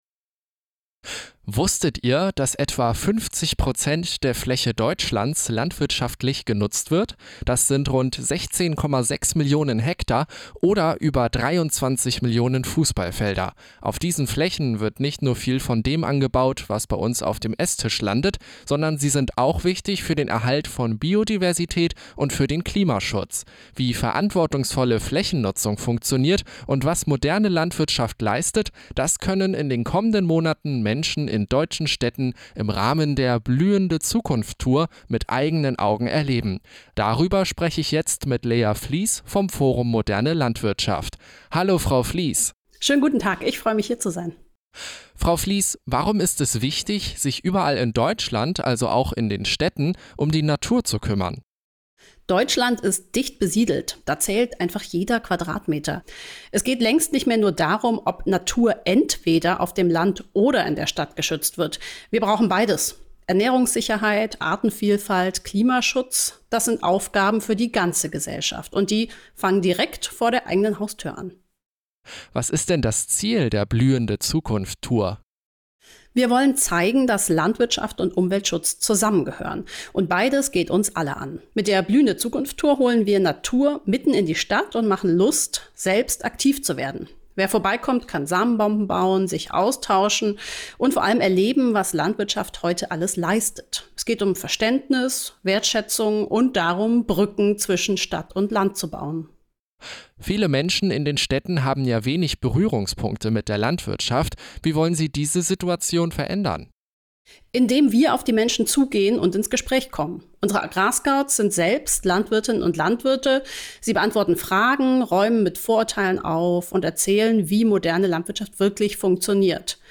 Landwirtschaft erleben, mitgestalten, verstehen: die "Blühende Zukunft Tour". Ein Interview